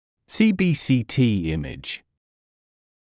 [siːbiːsiːtiː ‘ɪmɪʤ][сиːбиːсиːтиː ‘имидж]конусно-лучевая томограмма